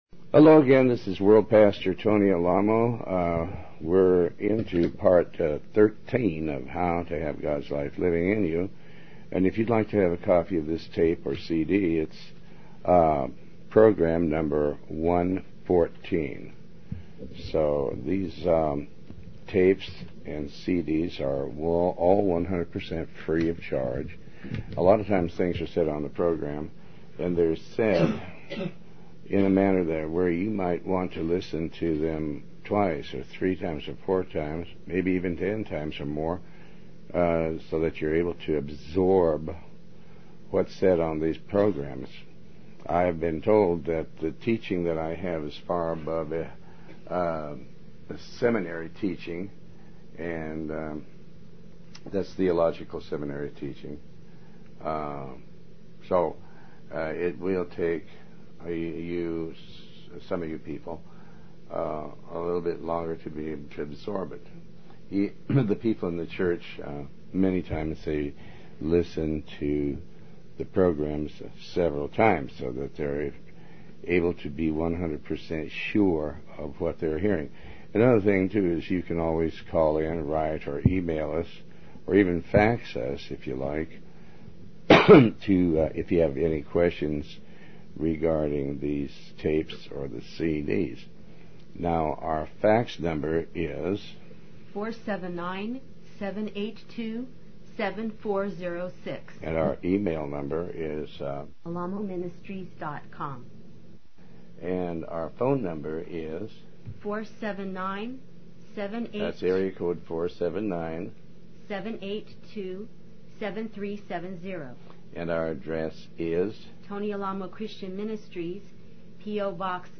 Talk Show Episode, Audio Podcast, Tony Alamo and Ep114, How To Have Gods Life Living In You, Part 13 on , show guests , about How To Have Gods Life Living In You, categorized as Health & Lifestyle,History,Love & Relationships,Philosophy,Psychology,Christianity,Inspirational,Motivational,Society and Culture